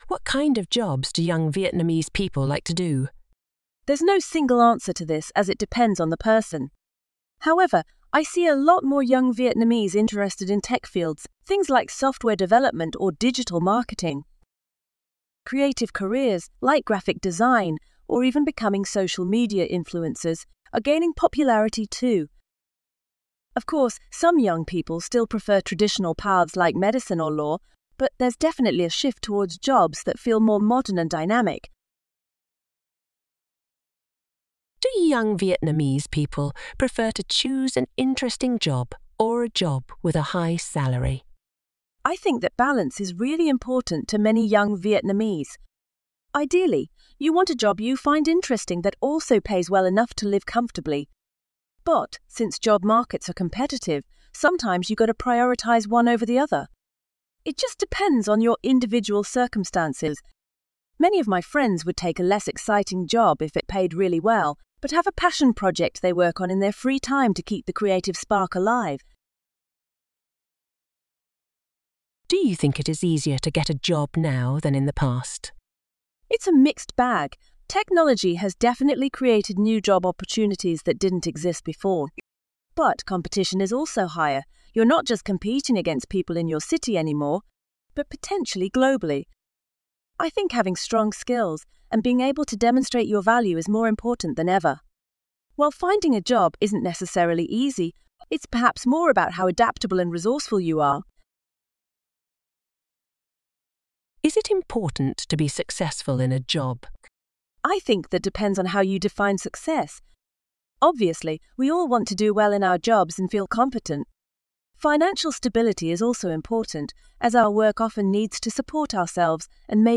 Trong bài viết này, Mc IELTS chia sẻ câu trả lời mẫu band 8.0+ từ cựu giám khảo IELTS, kèm theo các câu hỏi mở rộng kèm theo các câu hỏi mở rộng và bản audio từ giáo viên bản xứ để bạn luyện phát âm, ngữ điệu và tốc độ nói tự nhiên.